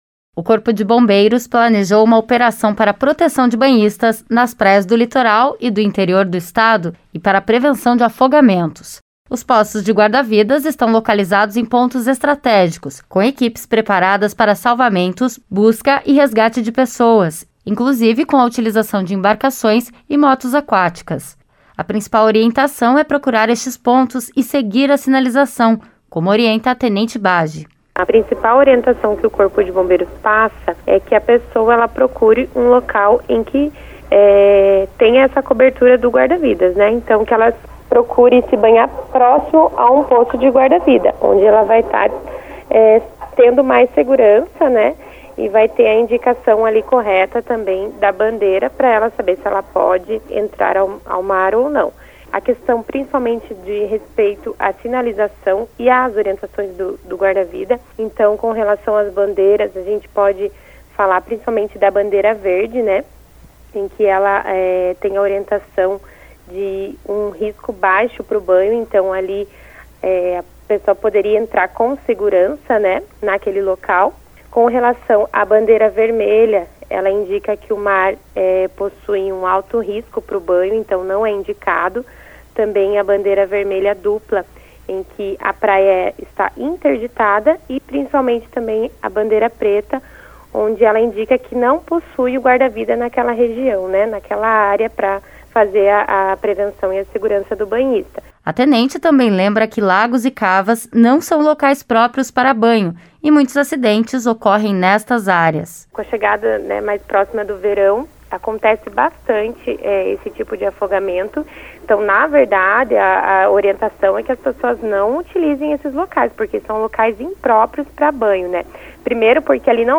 Bombeiros alertam para risco de afogamentos durante feriadão, nas praias do litoral e também do interior. A reportagem traz as dicas para manter a segurança durante o lazer.